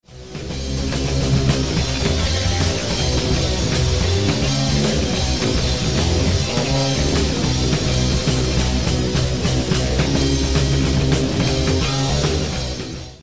Basse dans Avel Fal (1992), autre démonstration de basse chaotique
basse-avelfal-03.mp3